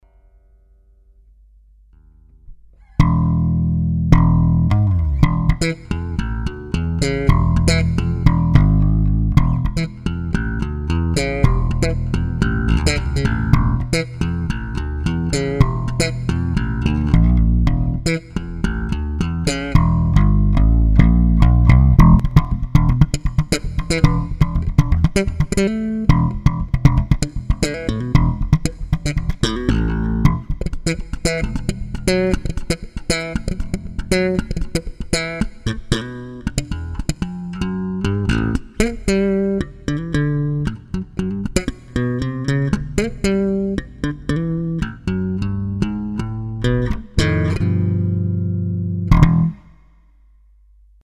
Violin solo